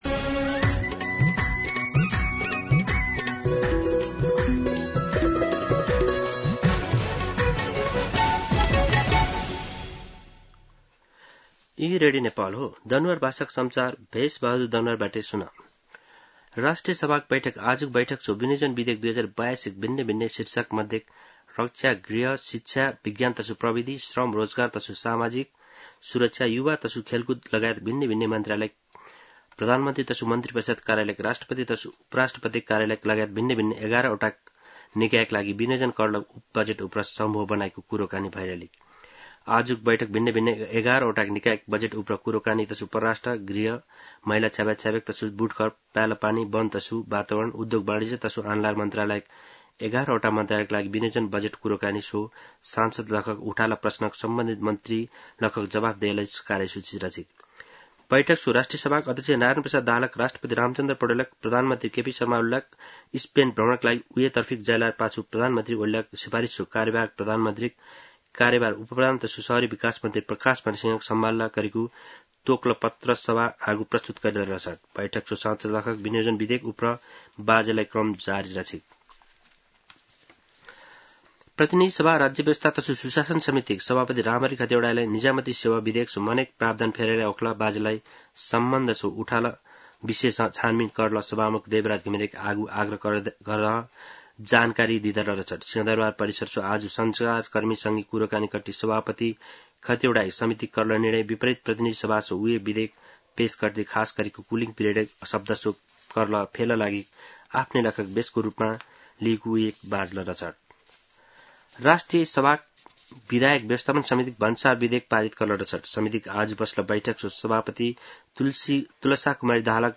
An online outlet of Nepal's national radio broadcaster
दनुवार भाषामा समाचार : १७ असार , २०८२